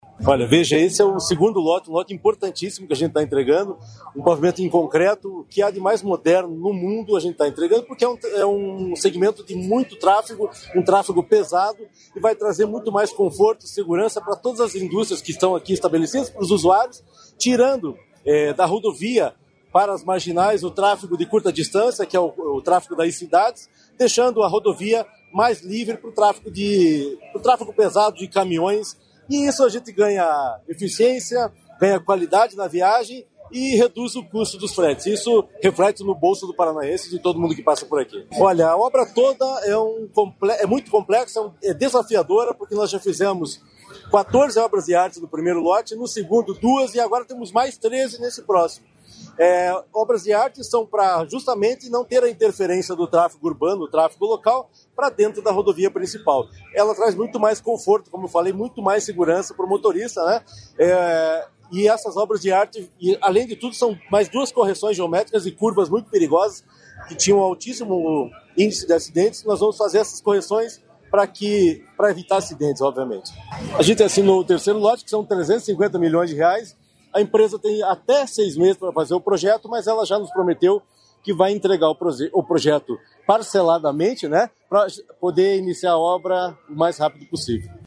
Sonora do presidente do DER, Fernando Furiatti, sobre a entrega da duplicação do Lote 2 da Rodovia dos Minérios